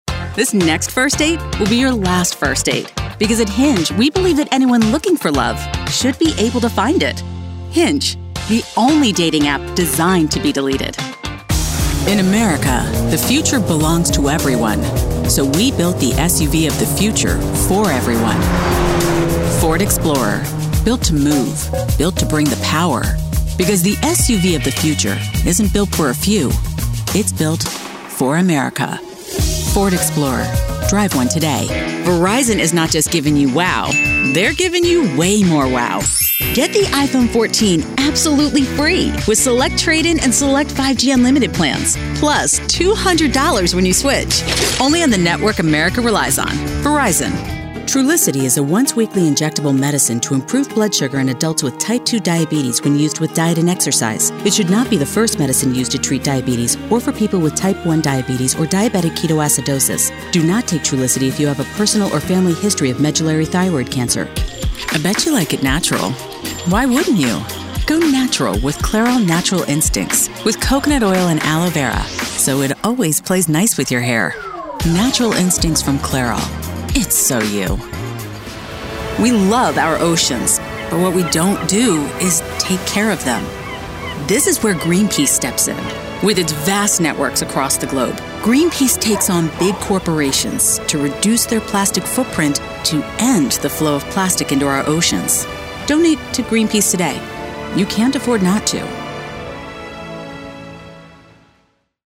Inglés (Estados Unidos)
Sociable
Relajante
Conversacional